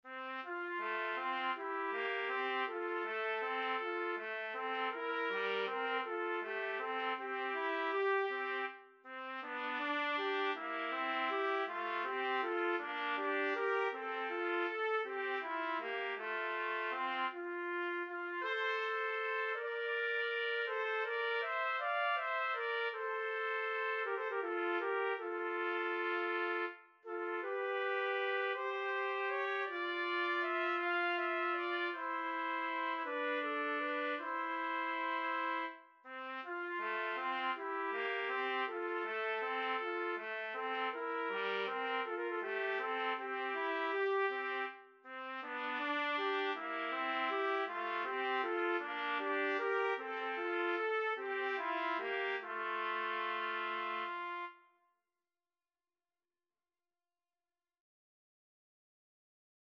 Trumpet 1Trumpet 2
6/8 (View more 6/8 Music)
Andante